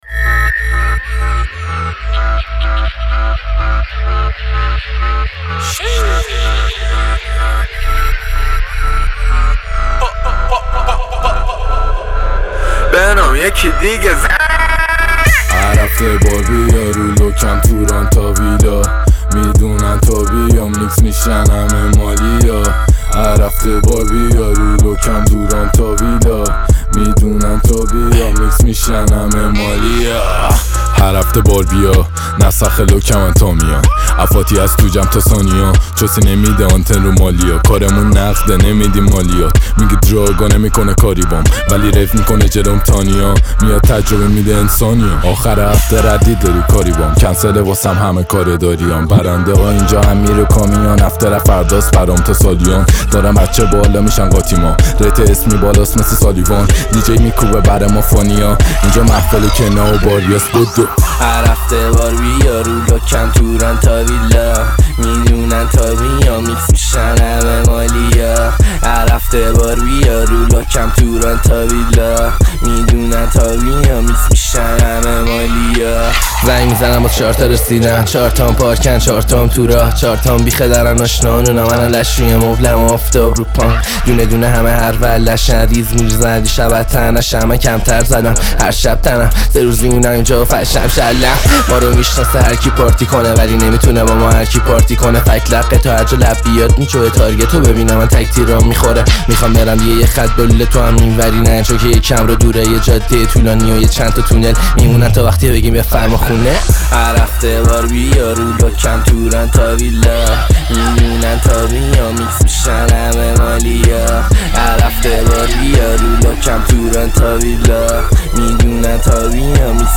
آهنگ جدید های جدید رپ فارسی های جدید